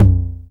Index of /90_sSampleCDs/Roland - Rhythm Section/DRM_Drum Machine/KIT_TR-909 Kit
TOM 909 TO02.wav